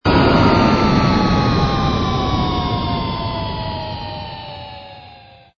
engine_ci_cruise_stop.wav